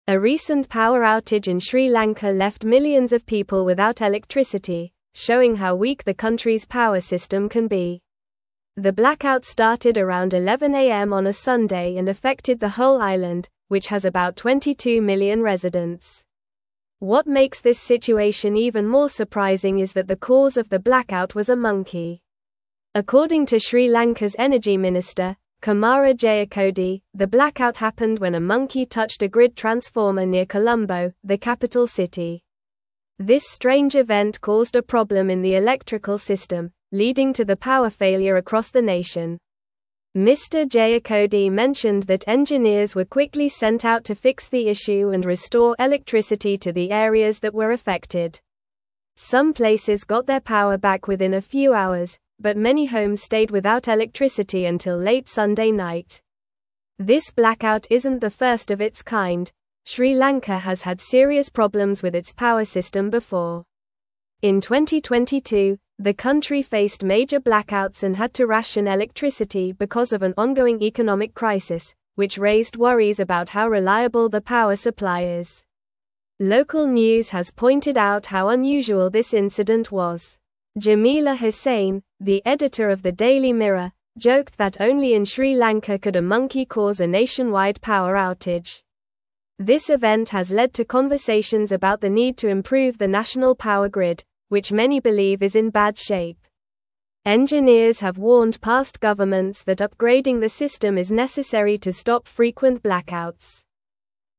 Normal
English-Newsroom-Upper-Intermediate-NORMAL-Reading-Monkey-Causes-Nationwide-Blackout-in-Sri-Lanka.mp3